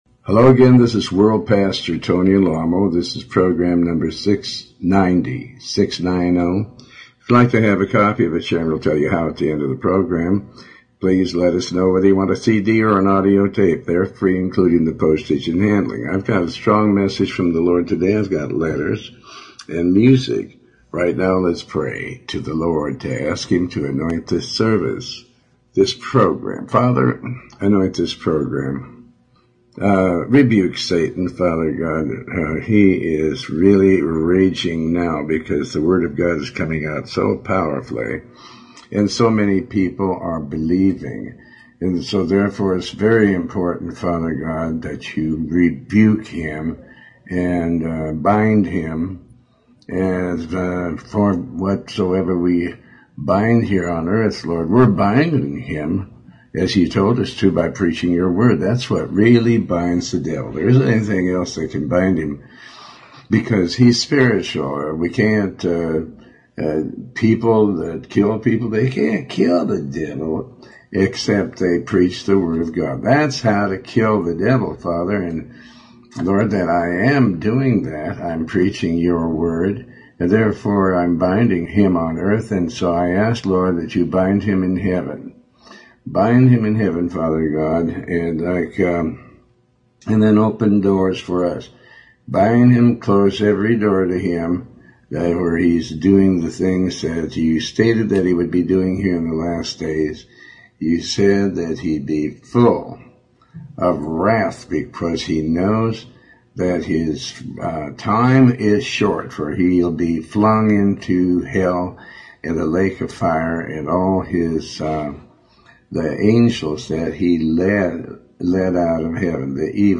Talk Show Episode, Audio Podcast, Tony Alamo and If you receive what you hear you very well might be saved today.